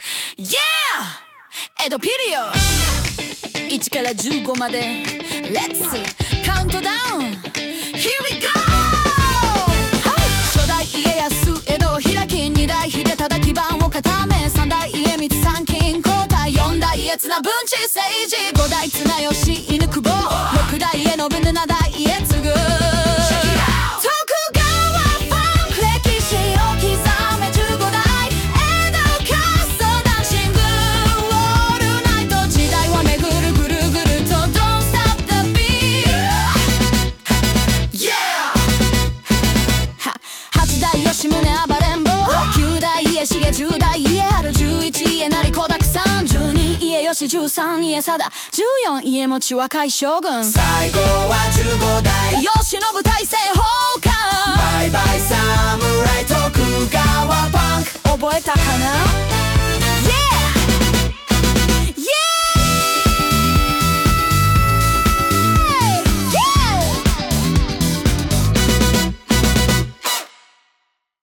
実際に一曲作ってみました。
徳川ファンク.mp3